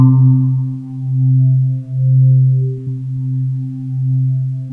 SWEEP   C2-L.wav